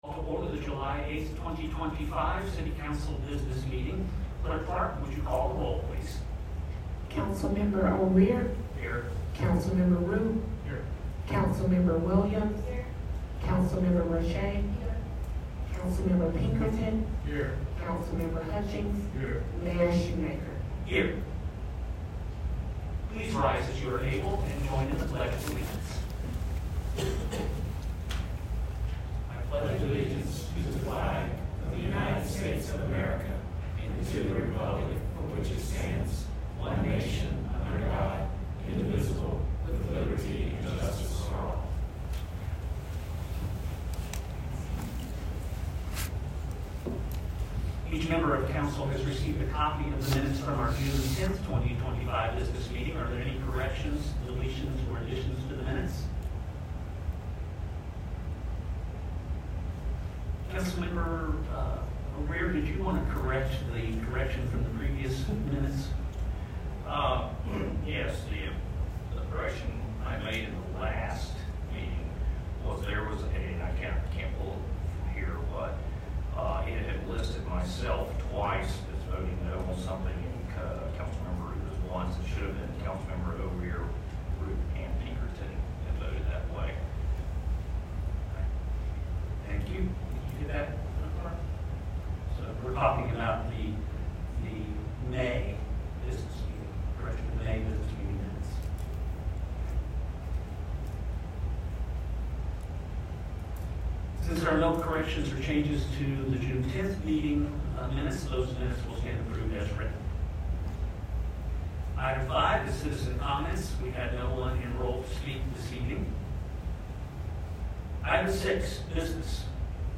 Simpsonville City Council held a regularly scheduled business meeting on July 8, 2025 in Council Chambers a.k.a. “The Ellipse.”
Present: Councilman Chad O’Rear (Ward 1), Councilman Aaron Rupe (Ward 2), Councilwoman Shannon Williams (Ward 3), Councilwoman Sherry Roche (Ward 4), Councilman Tim Pinkerton (Ward 5), Councilman Lou Hutchings (Ward 6), Mayor Paul Shewmaker